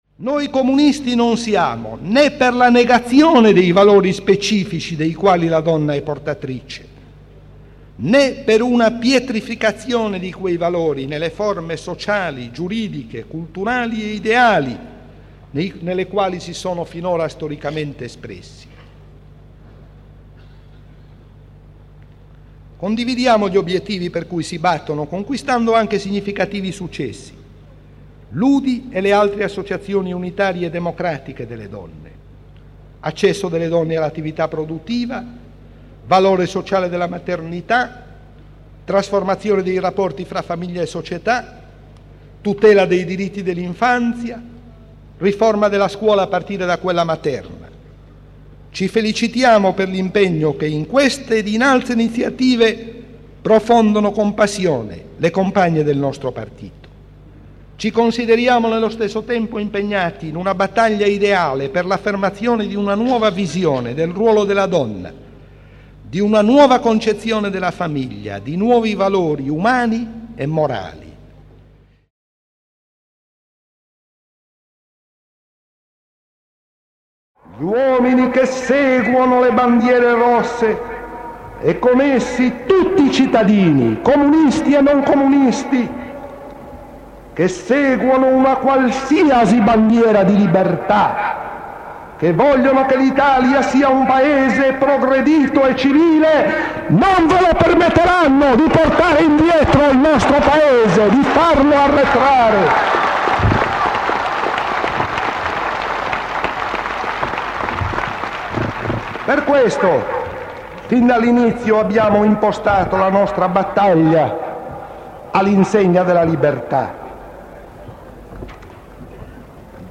Estratti audio da: Relazione al XIII Congresso del Pci
Manifestazione per il referendum sul divorzio